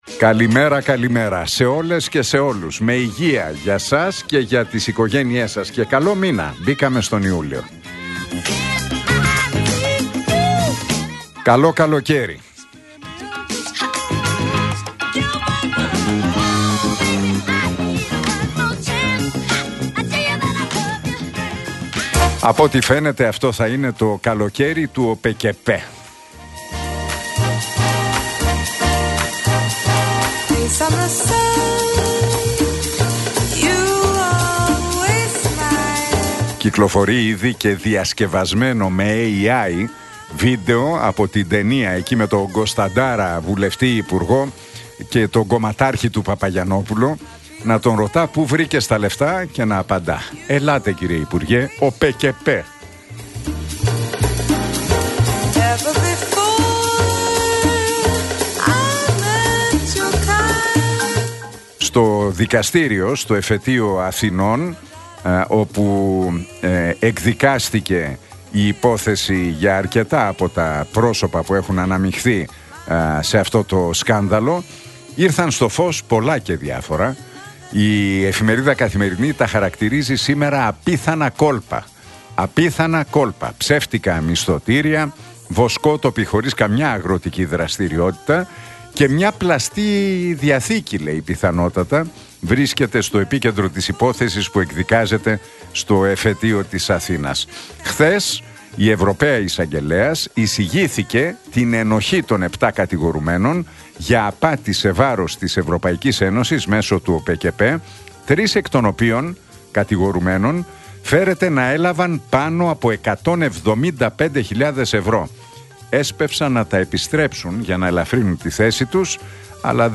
Ακούστε το σχόλιο του Νίκου Χατζηνικολάου στον ραδιοφωνικό σταθμό Realfm 97,8, την Τρίτη 1 Ιουλίου 2025.